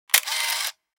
جلوه های صوتی
دانلود صدای دوربین 10 از ساعد نیوز با لینک مستقیم و کیفیت بالا